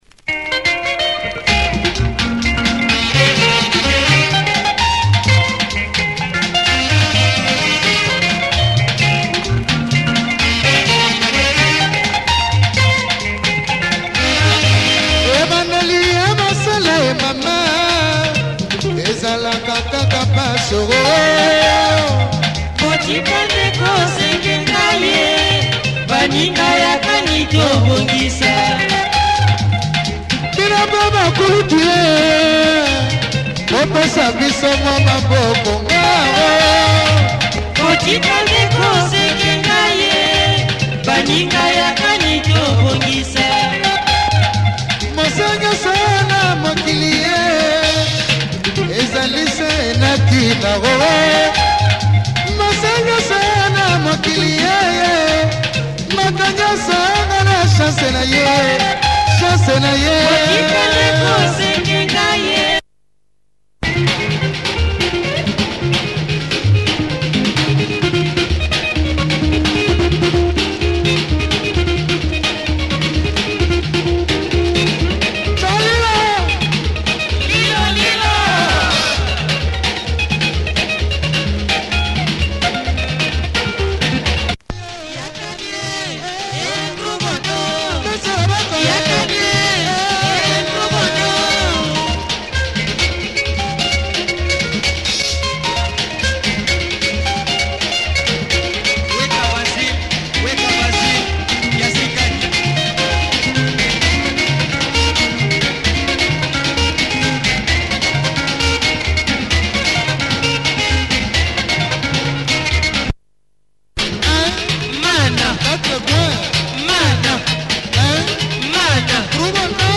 Classic lingala